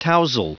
Prononciation du mot tousle en anglais (fichier audio)
Prononciation du mot : tousle